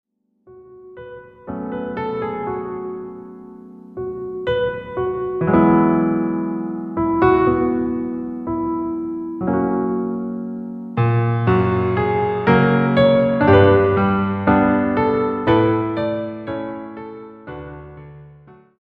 ぴあの
ピアノ練習中です。
とある曲の作曲がうまくいったのでそのノリでピアノバージョンにしてみました。
なかなか哀愁ある旋律で自信作に....なるかも^^;